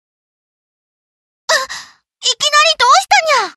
failsound.wav